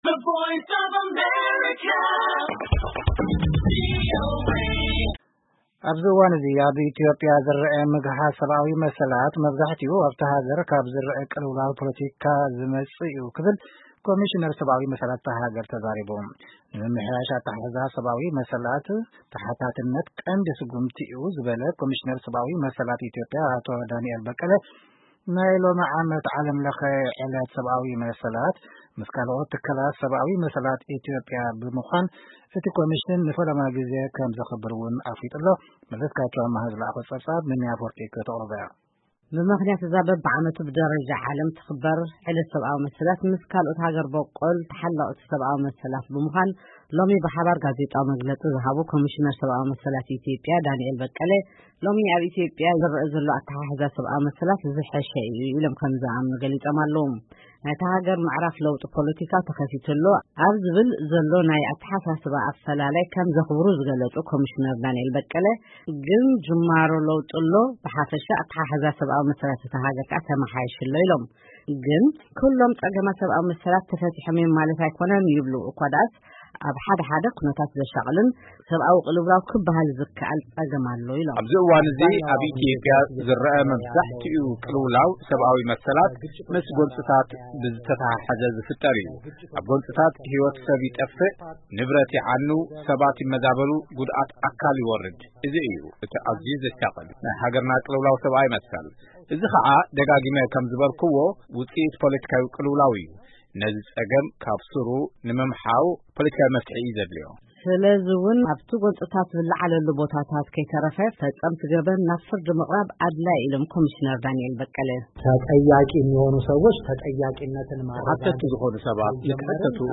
ብምኽንያት ኣብ ነፍሲ ወከፍ ዓመት ብደረጃ ዓለም ዝኽበር መዓልቲ ሰብኣዊ መሰላት ምስ ካልኦት ሃገር በቀል ተሓለቅቲ ሰብኣዊ መሰላት ብምዃን ሎሚ ብሓባር ጋዜጣዊ መግለጺ ዝሃቡ ኮሚሽነር ሰብኣዊ መሰላት ኢትዮጵያ ዳንኤል በቀለ ሎሚ ኣብ ኢትዮጵያ ዝረአ ኣተሓሕዛ ሰብኣዊ መሰላት ዝሓሸ’ዩ ኢሎም ከምዝአምኑ ገሊጾም።